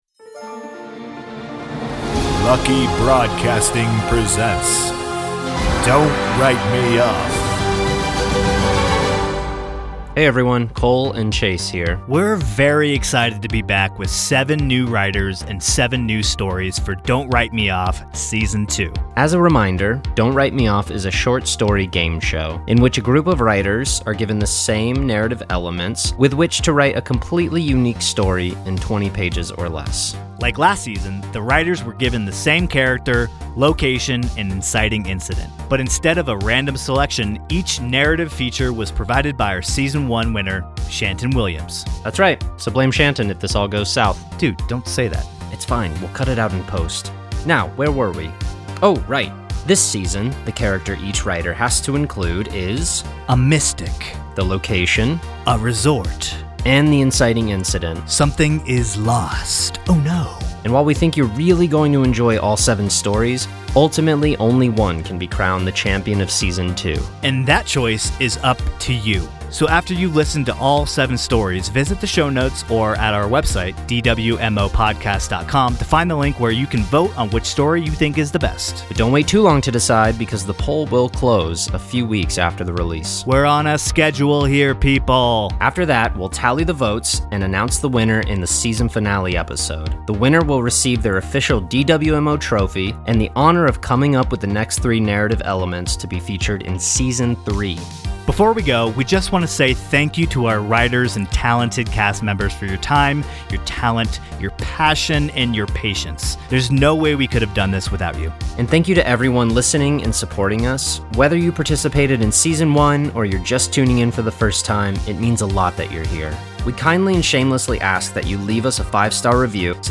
Don’t Write Me Off is an immersive, short-form audio drama competition.